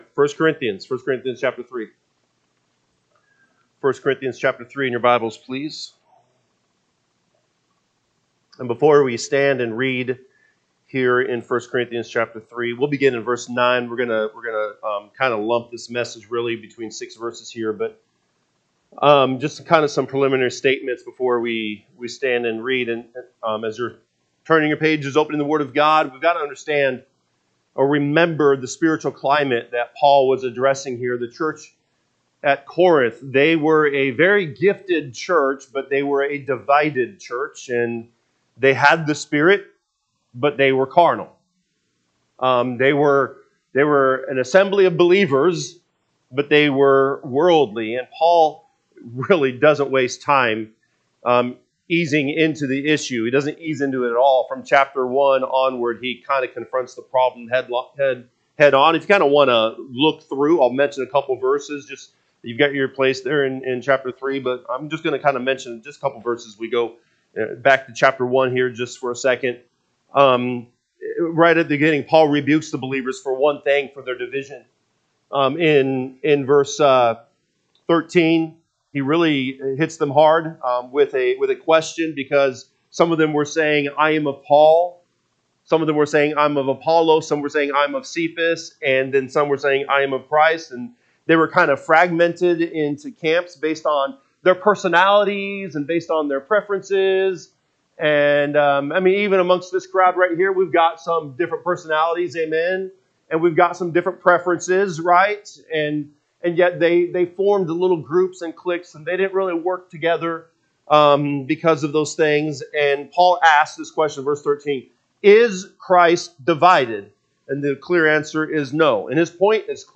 August 17, 2025 pm Service 1 Corinthians 3:9-15 (KJB) 9 For we are labourers together with God: ye are God’s husbandry, ye are God’s building. 10 According to the grace of God…